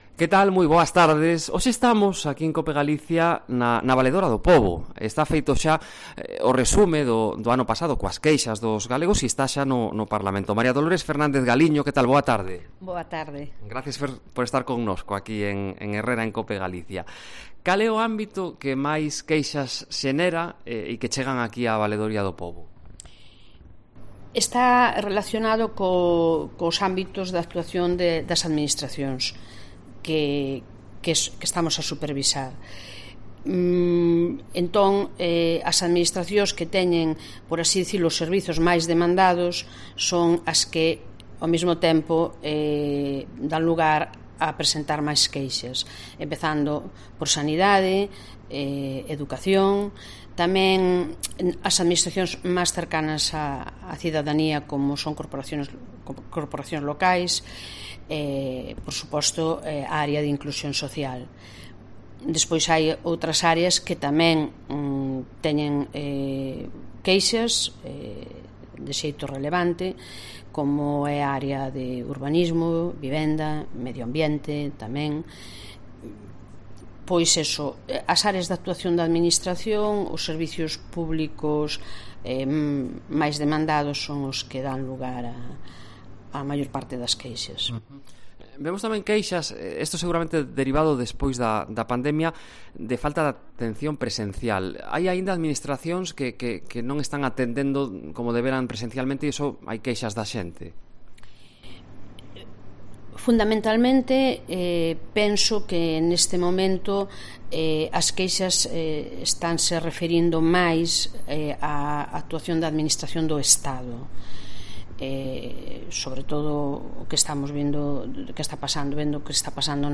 Destácado Fernández Galiño nunha entrevista en Herrera en COPE en Galicia.